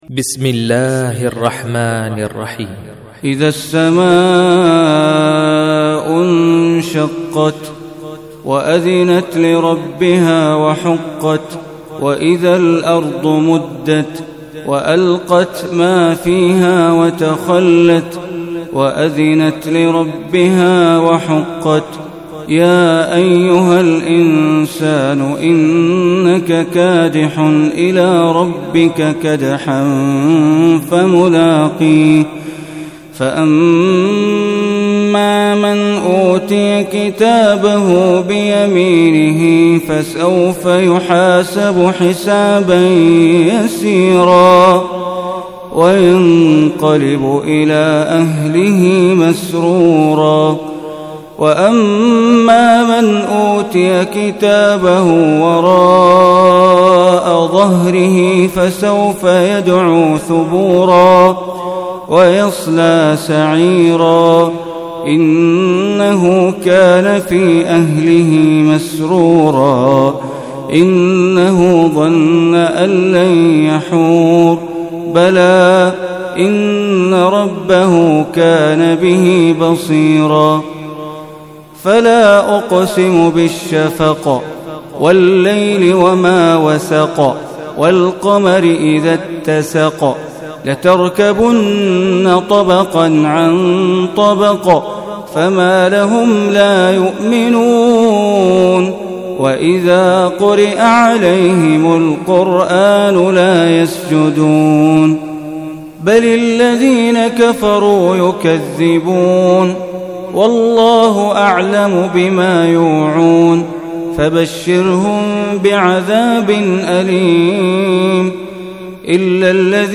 Surah Inshiqaq Recitation by Sheikh Bandar Baleela